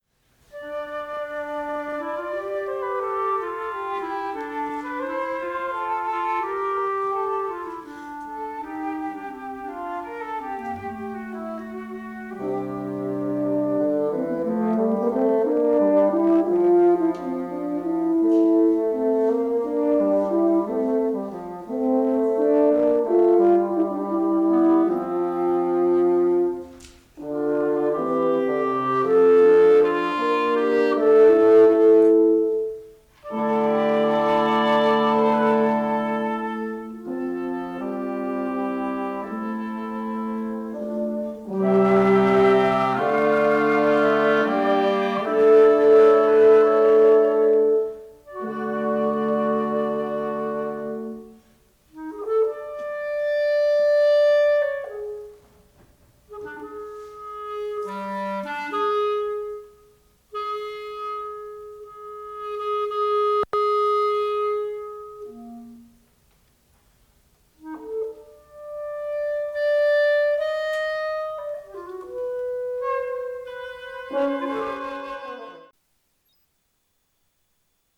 opening of Fantasia for Woodwind Quintet
This piece began life as an exercies to write three phrases in the style of Ockeghem: the first having two voices, then three, then four. The third phrase is more like something a hundred years after Ockeghem.